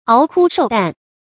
熬枯受淡 注音： ㄠˊ ㄎㄨ ㄕㄡˋ ㄉㄢˋ 讀音讀法： 意思解釋： 指忍受清苦的生活。